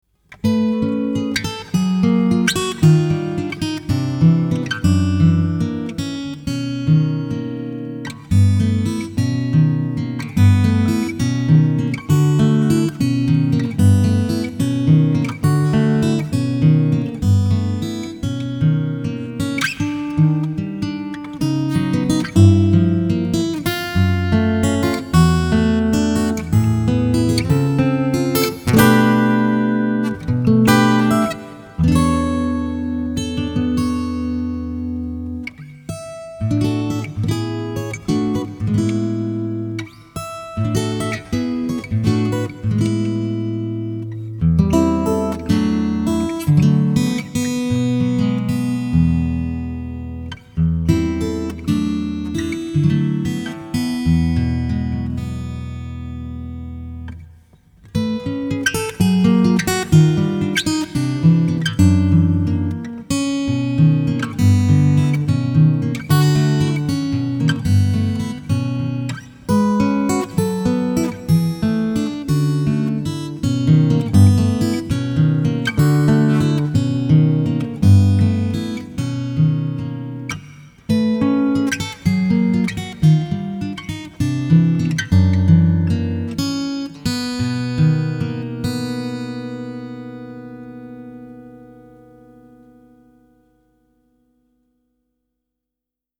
【Fusion爵士】